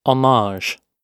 /ɒˈmɑːʒ/). ↩